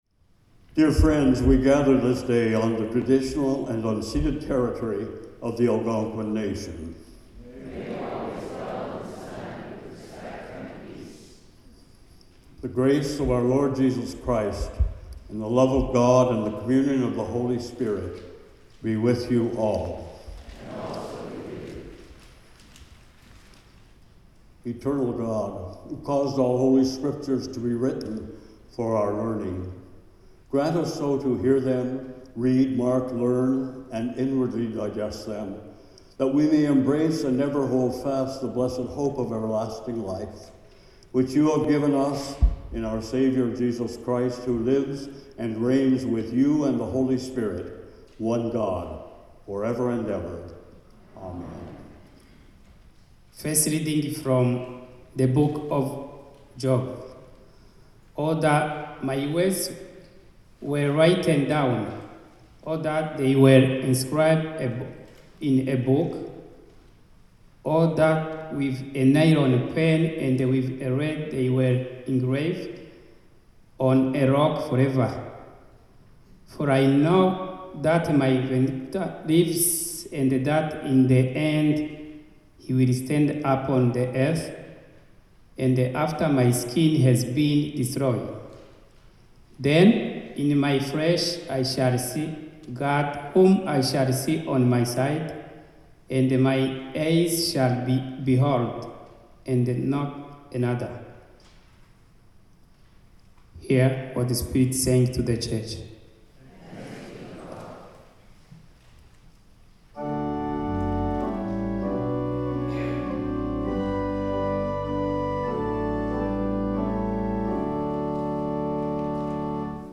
Hymn 593: O God of Every Nation
Last Post & Reveille The Lord’s Prayer (sung)